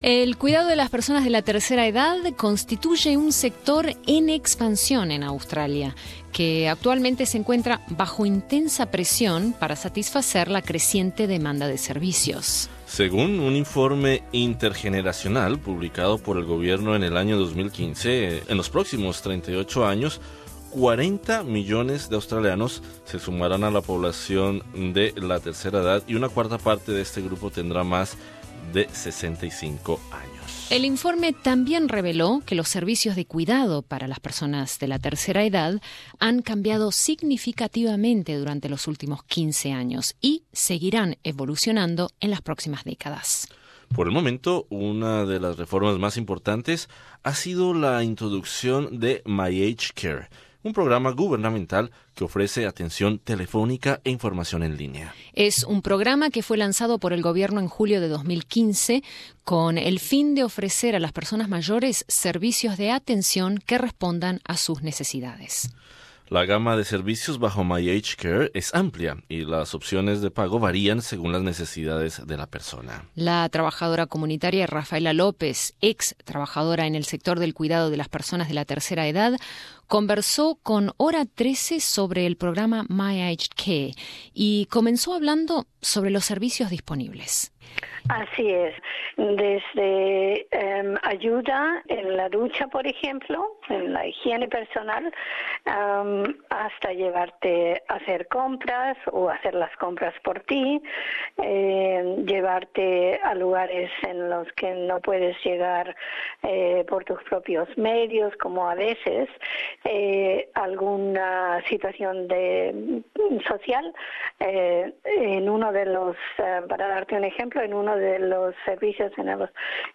Entrevista con la trabajadora comunitaria y ex-trabajadora en el sector del cuidado para las personas de la tercera edad